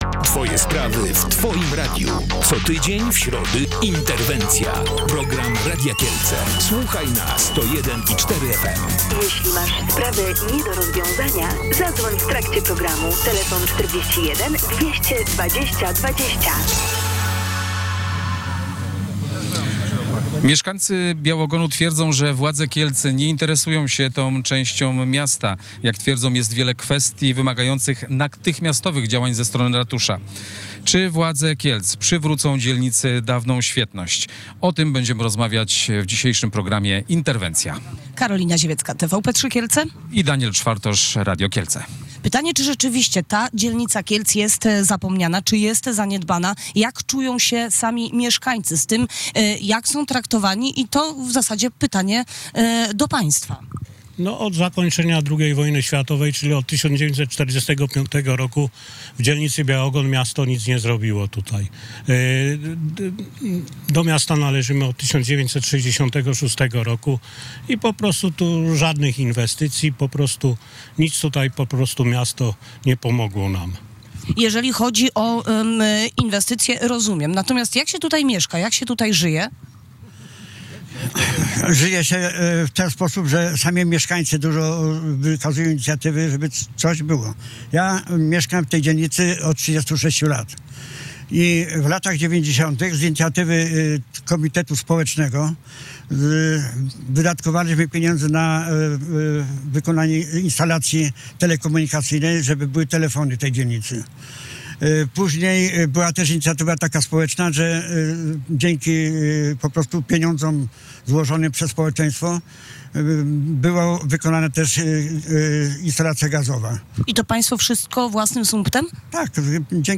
– Stwierdzenie, że miasto nie realizuje żadnych inwestycji w dzielnicy Białogon jest mocno przesadzone – uważa Łukasz Syska, wiceprezydent Kielc.